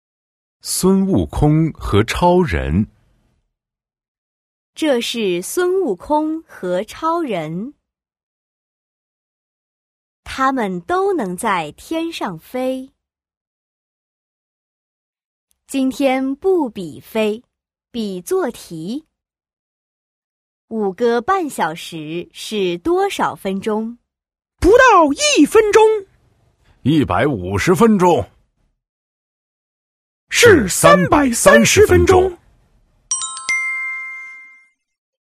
Đọc truyện